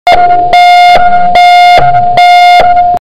Fire Alarm Sound. Téléchargement d'Effet Sonore
Fire Alarm Sound. Bouton sonore